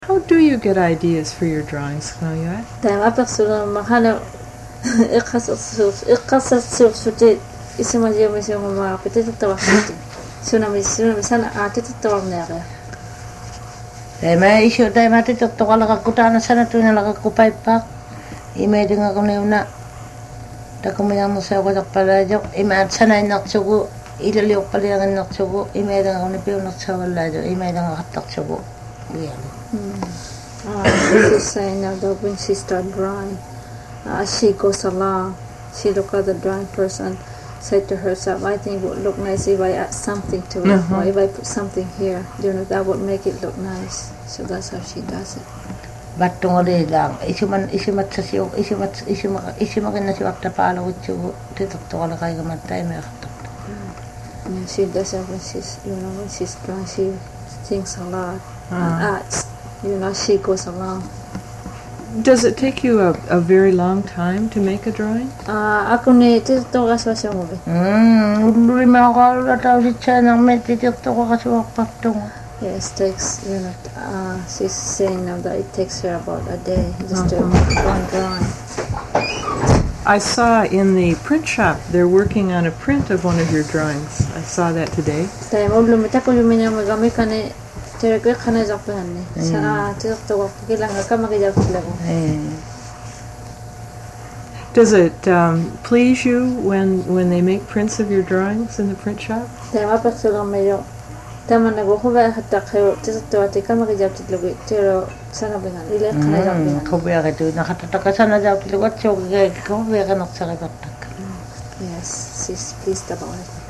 Interview
Translated